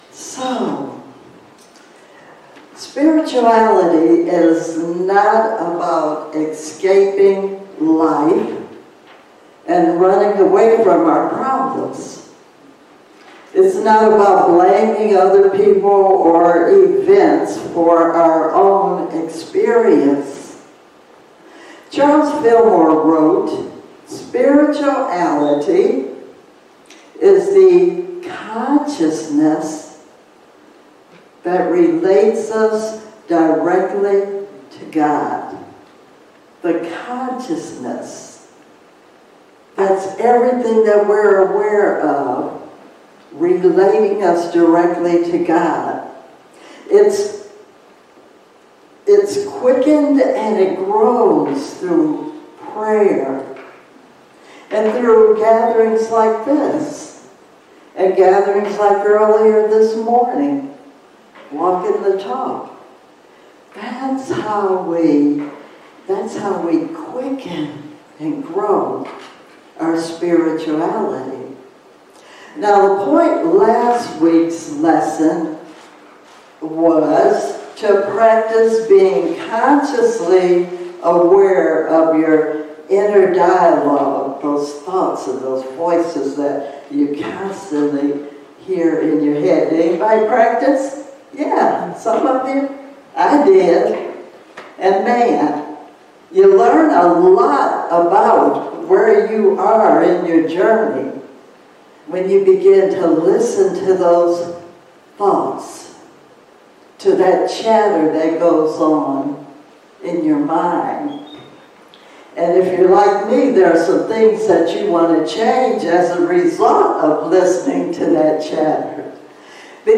Series: Sermons 2022